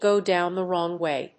アクセントgò dówn the wróng wáy